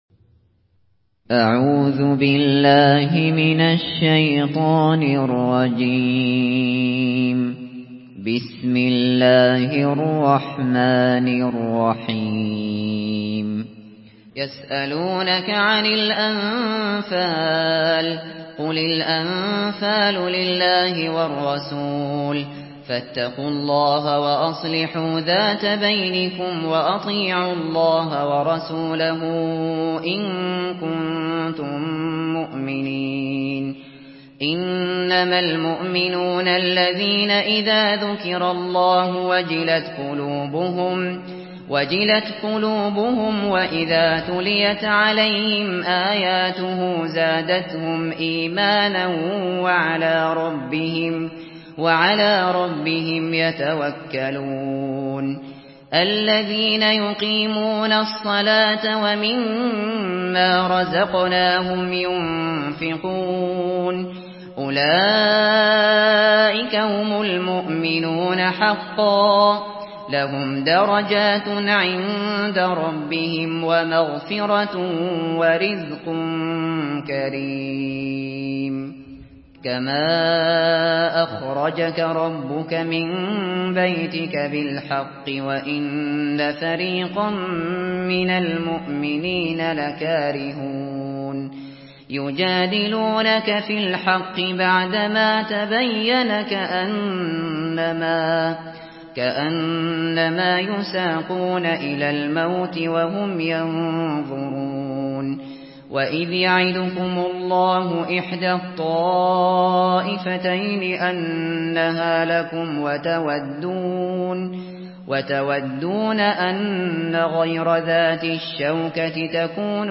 Surah আল-আনফাল MP3 in the Voice of Abu Bakr Al Shatri in Hafs Narration
Murattal Hafs An Asim